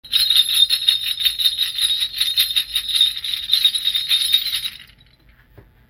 Small Brass Bells
Beautiful brass, clam style embossed jingle bells.